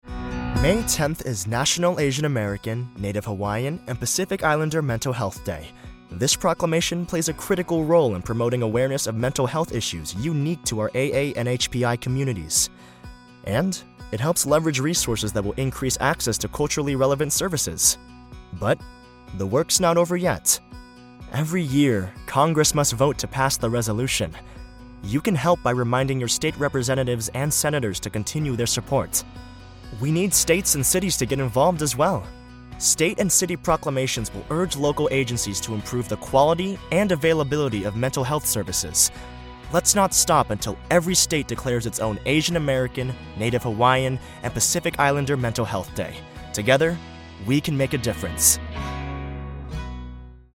Teenager, Adult, Young Adult
standard us
commercial
comedic
conversational
professional home studio